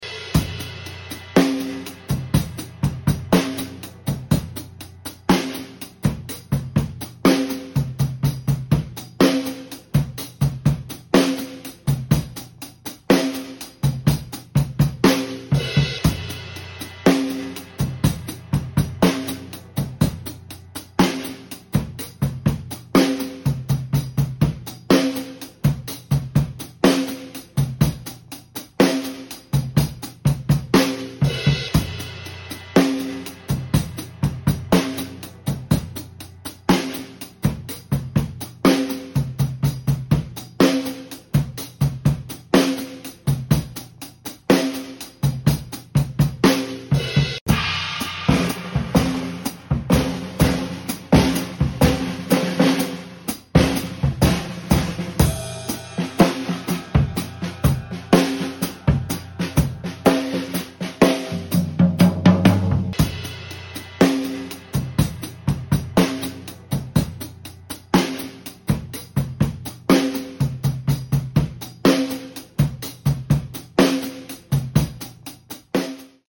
We had a great day this past Tuesday at the Hive Rooms with Dave Mattacks. Great drumming from all participants!